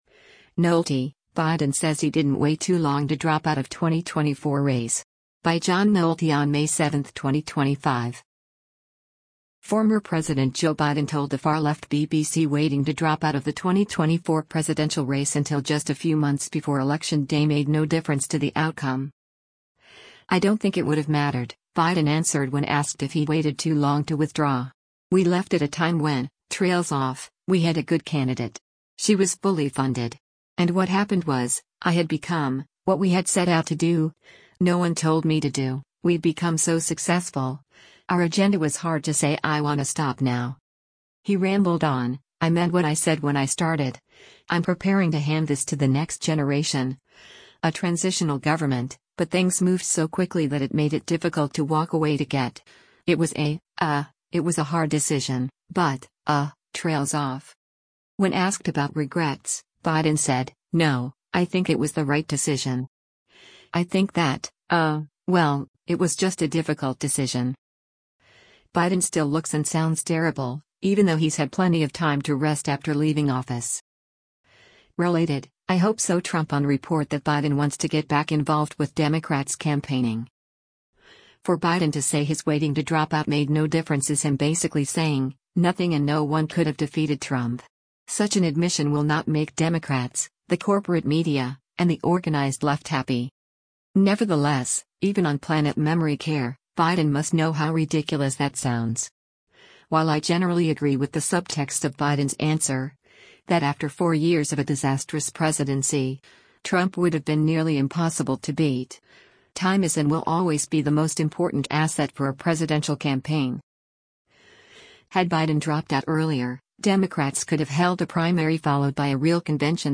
Biden still looks and sounds terrible, even though he’s had plenty of time to rest after leaving office.